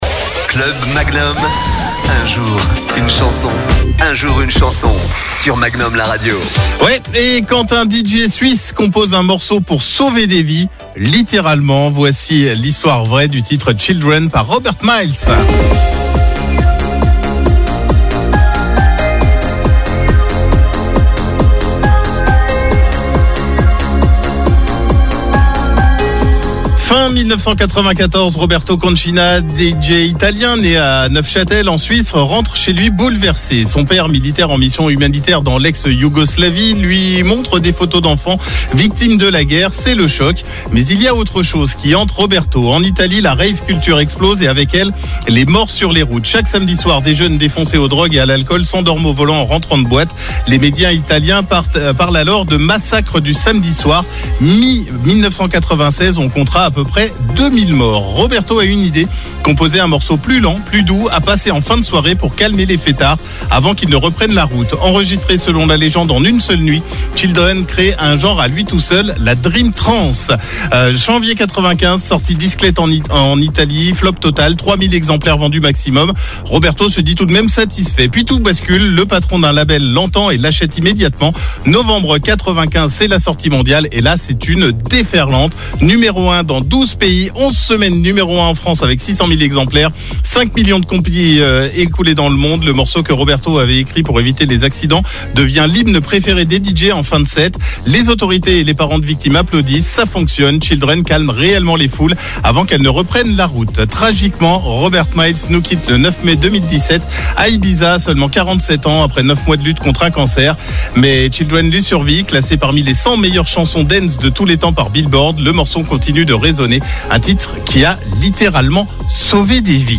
Dream Trance
composer un morceau plus lent, plus doux